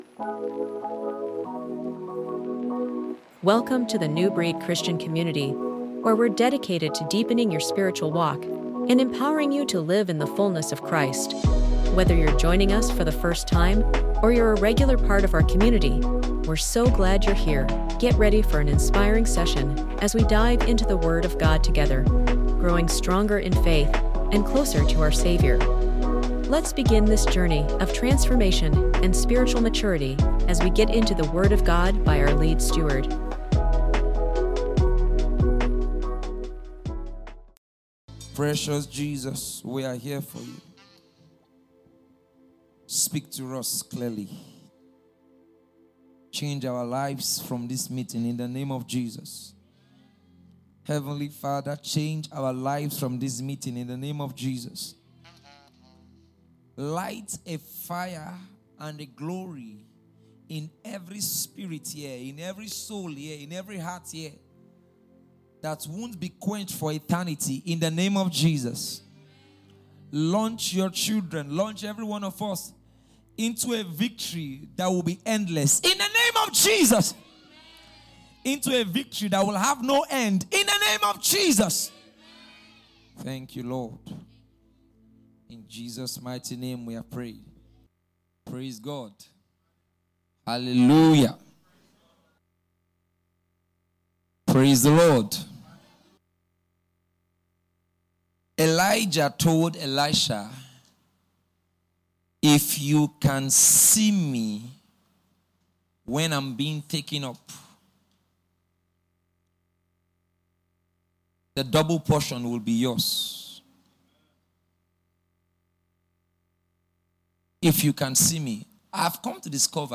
Home About Us Sermons Steadfast Devotions Partner With Us Testimonies Contact Us For I Long To See You | Day 3 Welcome to Newbreed Christian Community! We’re excited to bring you a powerful teaching from our special program held in the city of Port Harcourt, titled: 📖 “For I Long To See You”